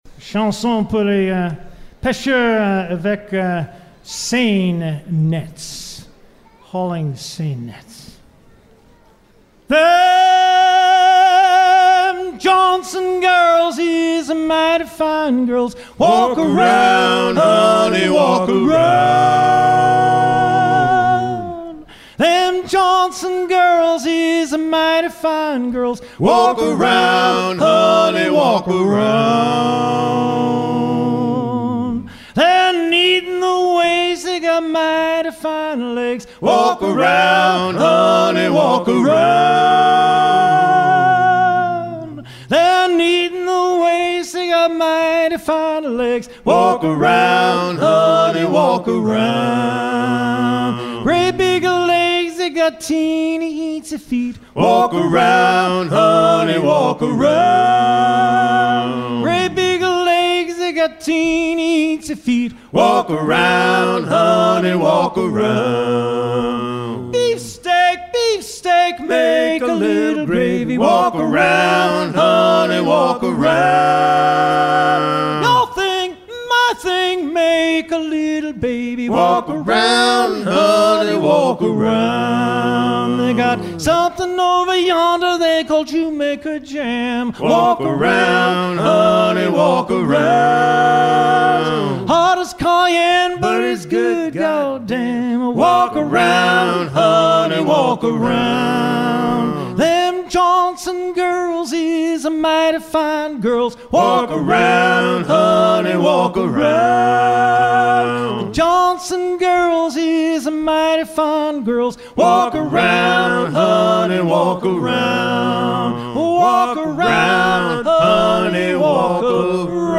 gestuel : à lever les filets
circonstance : maritimes
en concert